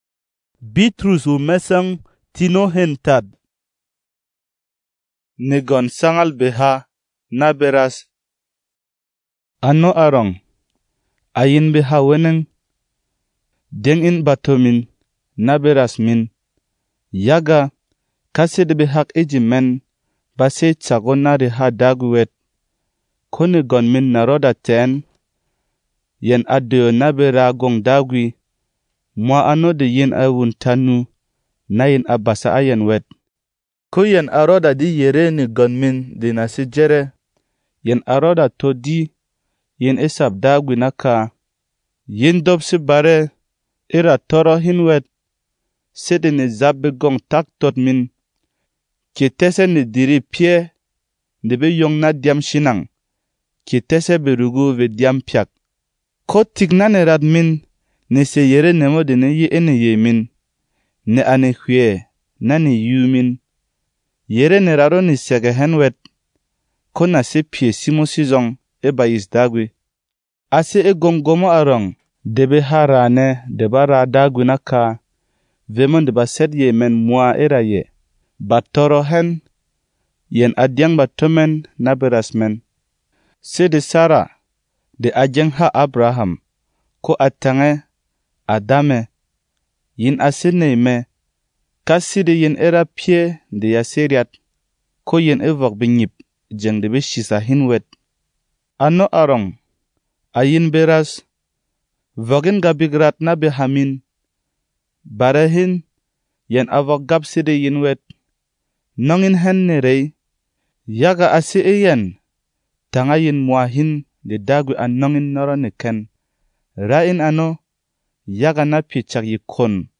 Berom MP3 Bible ⚙
Non-Drama